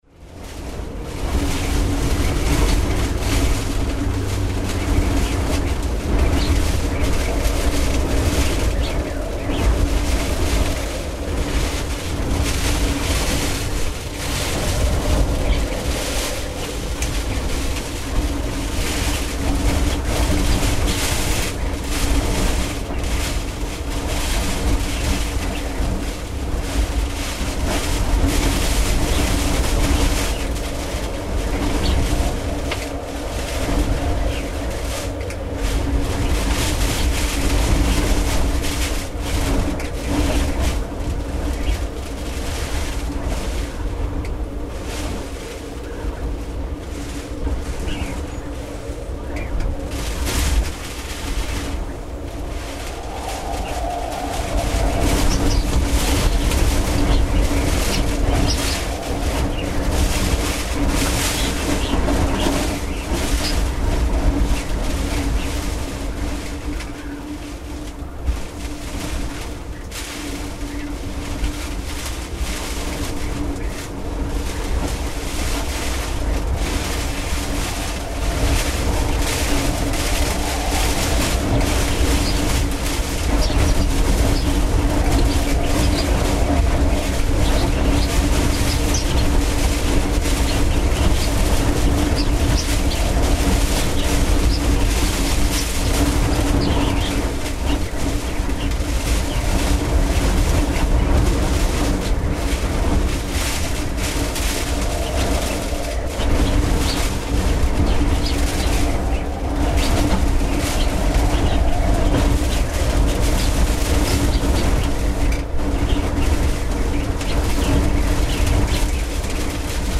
Óveður í Sundahöfn
Þann 11.desember 2008 gerði suðaustan hvell. Fylgdi þessu mikil rigning með mjög snörpum vindhviðum.
Milli kl 21:30 og 22 fór ég út á Sundahafnarsvæðið á verkstæðisbílnum og hljóðritaði ósköpin inni í bílnum.
Upptakan er gerð á Olympus LS10 og á innbyggðu hljóðnemana í 44,1 kHz /16 bit sniði.
ovedur.mp3